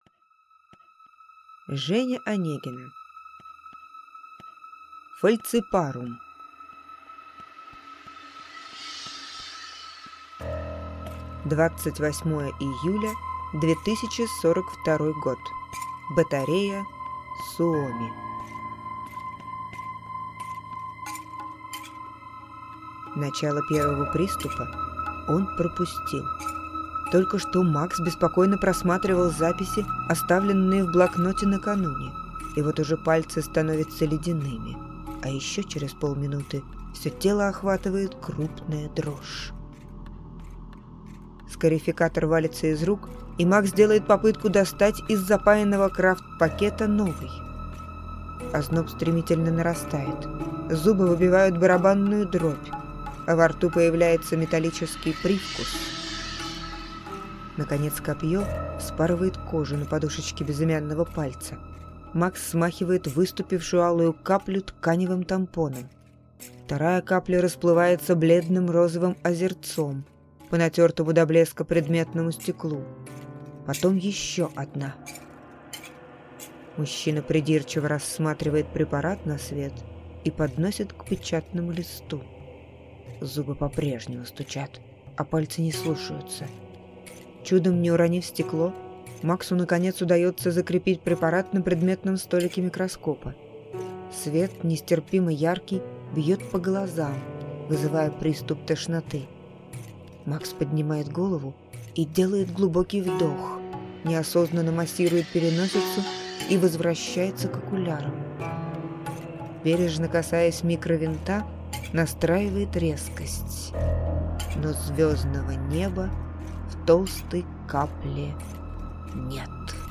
Аудиокнига Falciparum | Библиотека аудиокниг